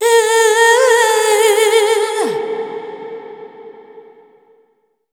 05-Ethnic Female 1.wav